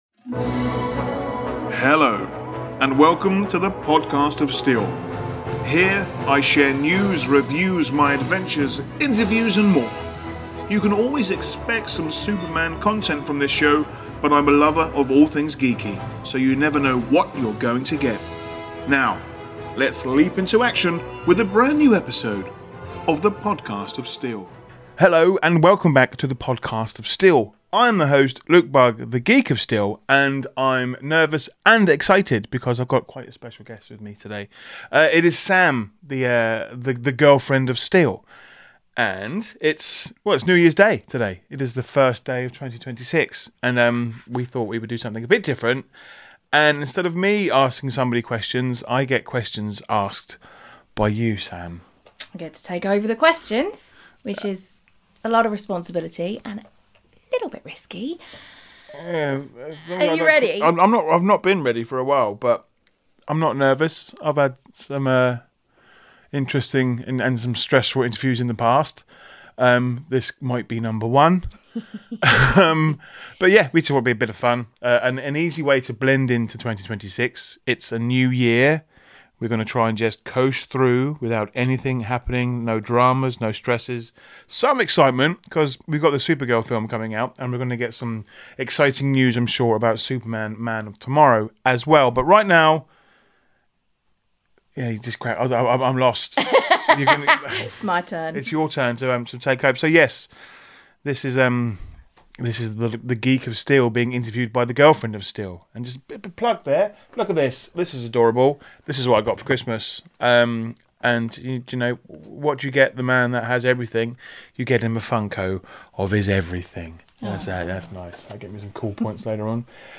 No scripts.